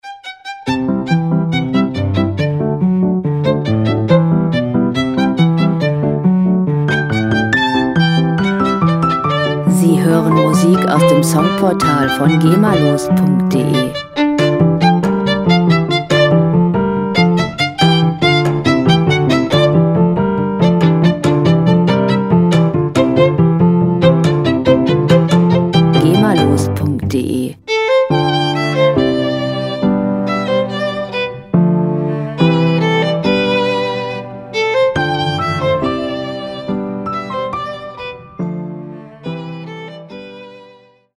• Salonmusik